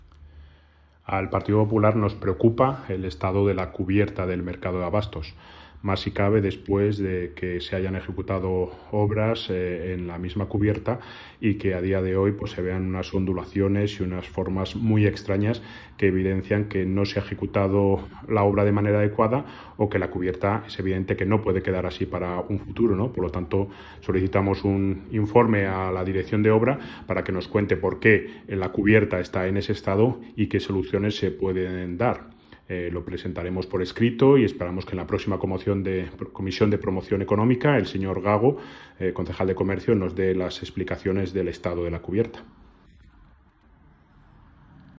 Declaraciones-cubierta-mercado-de-abastos.wav